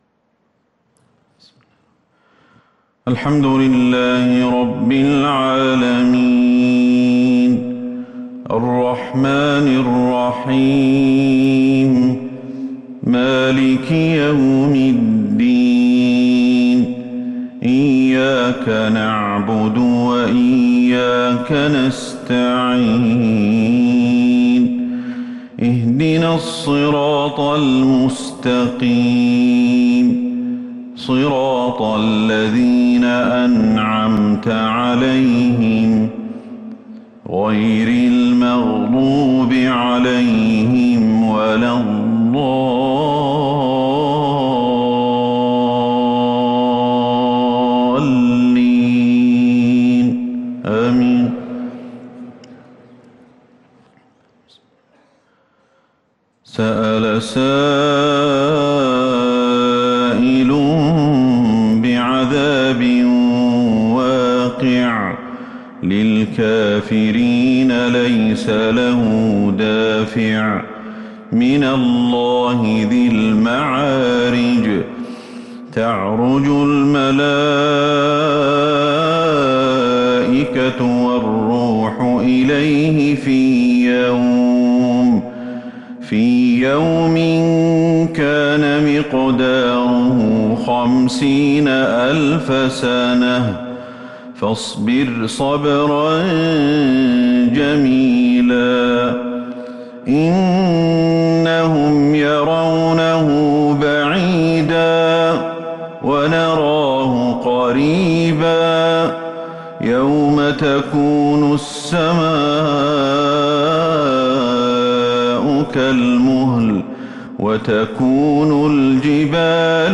صلاة العشاء
تِلَاوَات الْحَرَمَيْن .